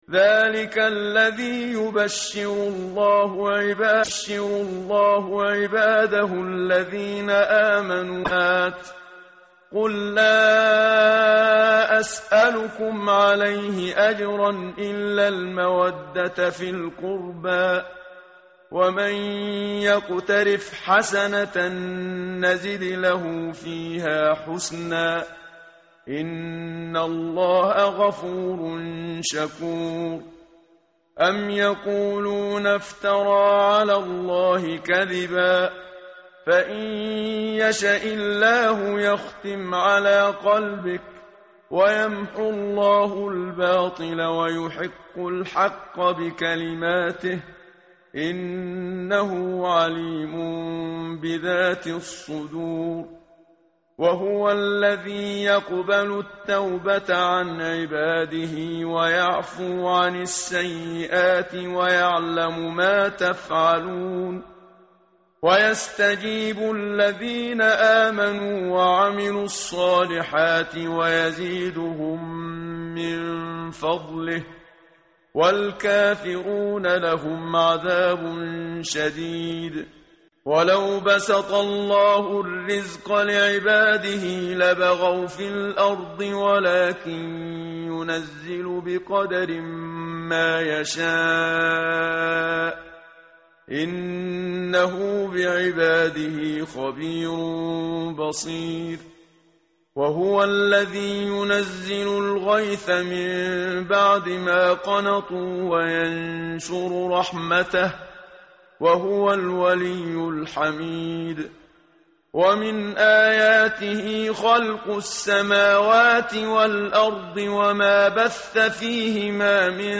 متن قرآن همراه باتلاوت قرآن و ترجمه
tartil_menshavi_page_486.mp3